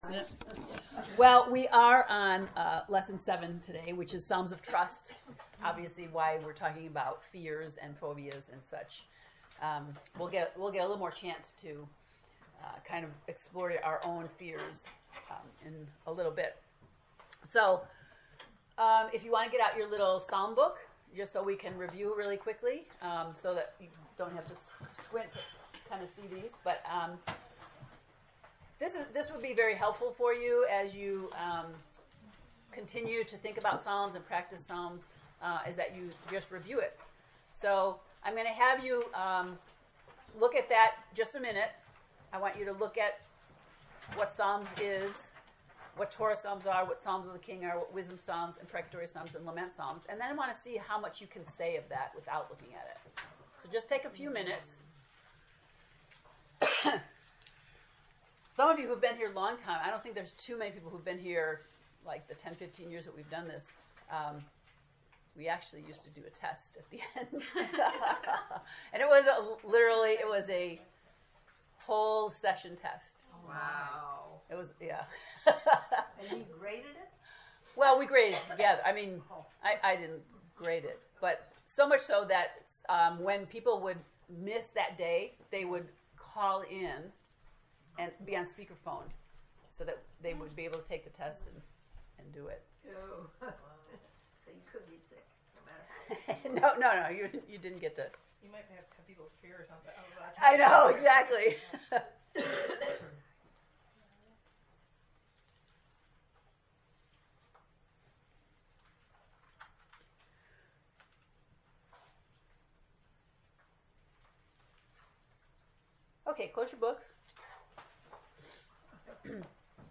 To listen to the lesson 7 lecture, “Psalms of Trust,” click below:
psalms-lect-7.mp3